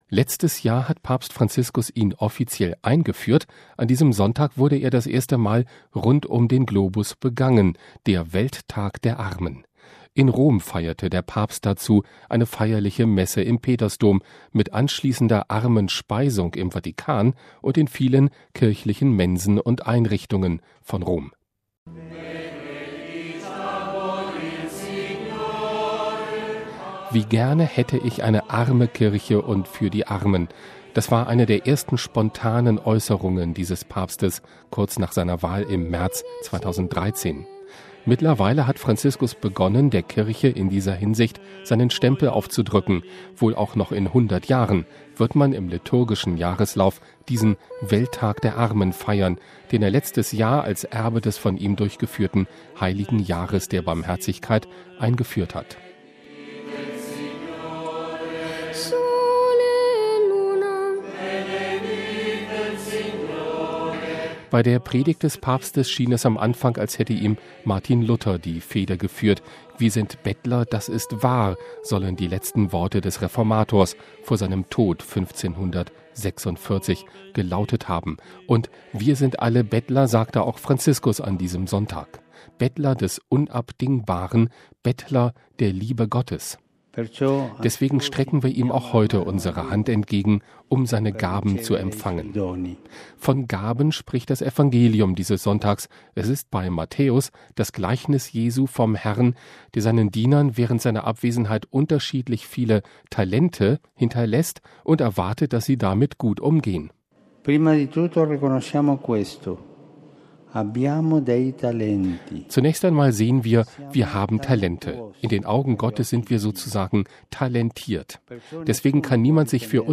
Letztes Jahr hat Papst Franziskus ihn offiziell eingeführt, an diesem Sonntag wurde er das erste Mal rund um den Erdball begangen: der Welttag der Armen. In Rom feierte der Papst dazu eine feierliche Messe im Petersdom, mit anschließender Armenspeisung im Vatikan und in vielen kirchlichen Mensen und Einrichtungen von Rom.